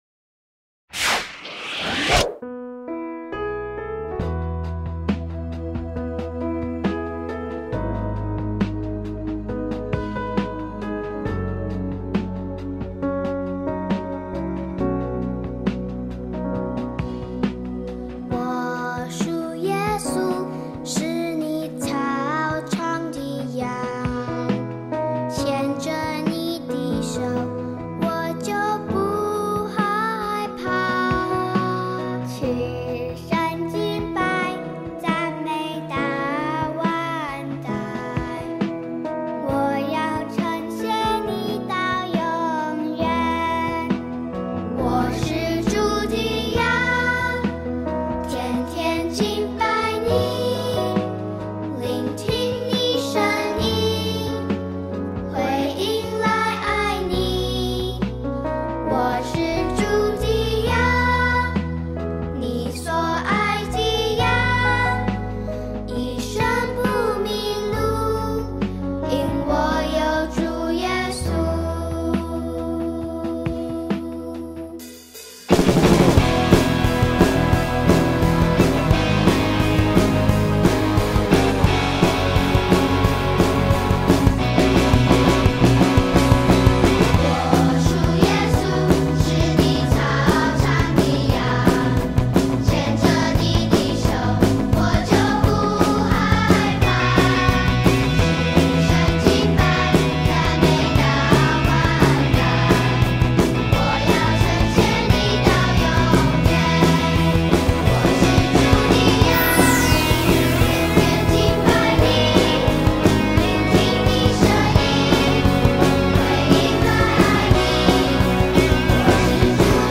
视频里有动作演示，音频里歌会自动重复三遍。